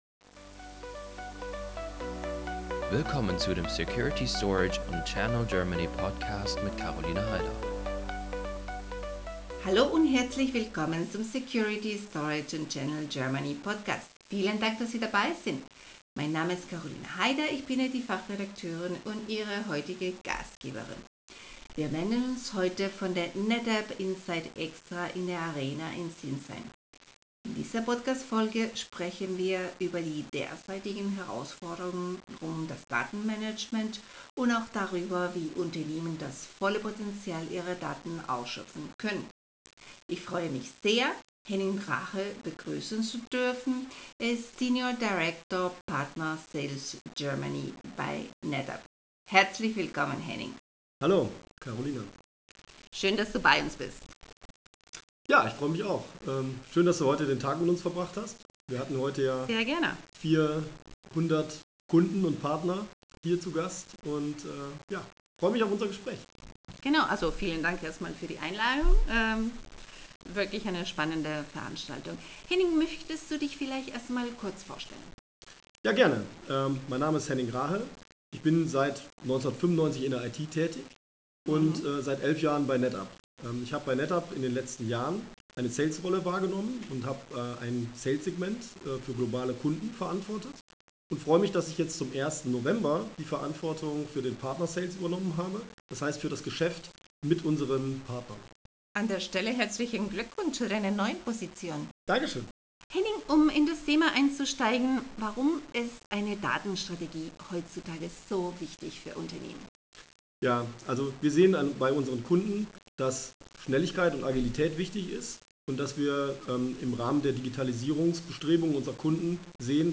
Estamos aquí hoy desde el NetApp Insight Extra en la Arena de Sinsheim. En este episodio del podcast hablamos de los desafíos actuales en torno a la gestión de datos y cómo las empresas pueden aprovechar todo el potencial de sus datos.